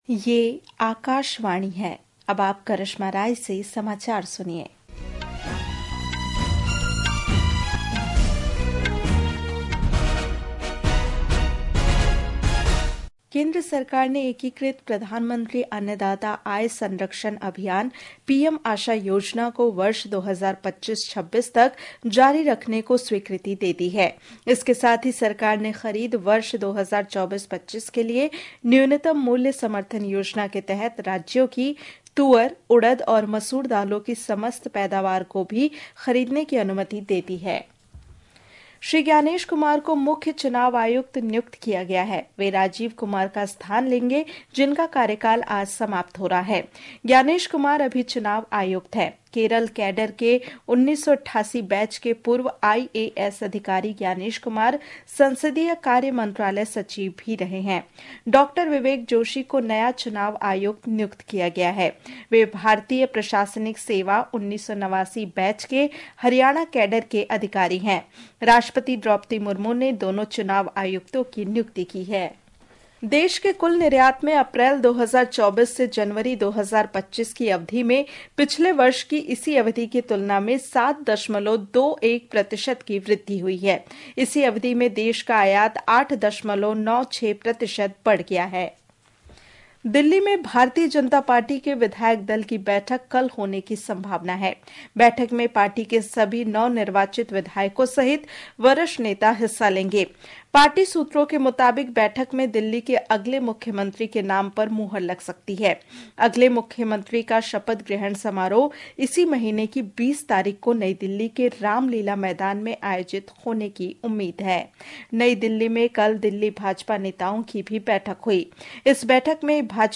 प्रति घंटा समाचार